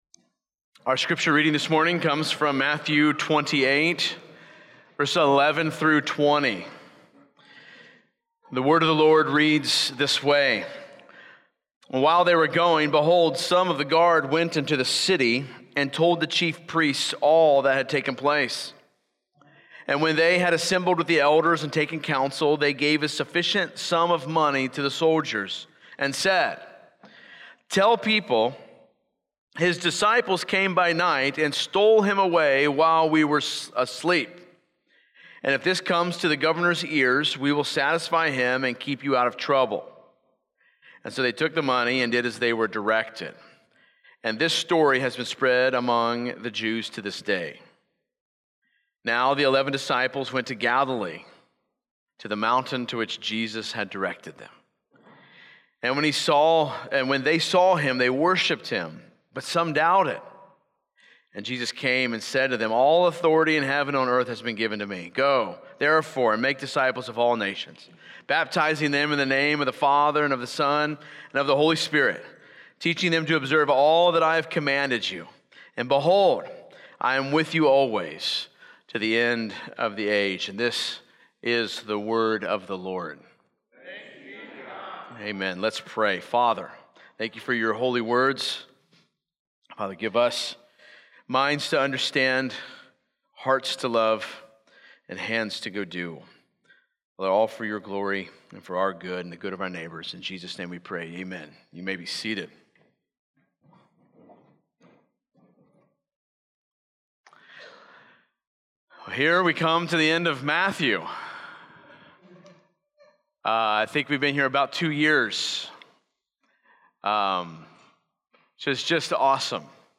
Weekly Sunday AM sermon from Christ the Lord Church in Dayton, Ohio.